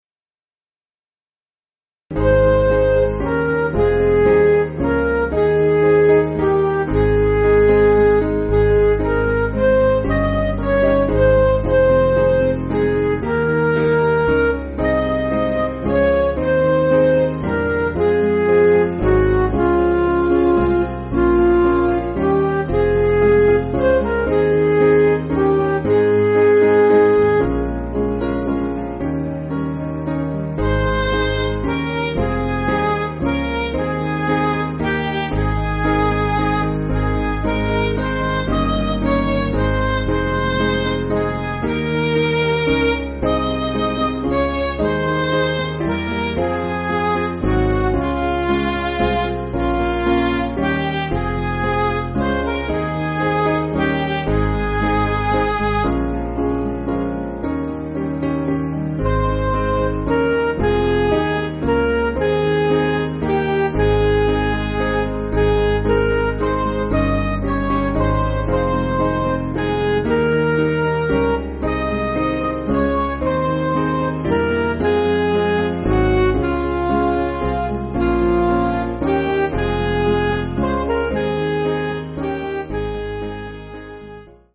Piano & Instrumental
(CM)   6/Ab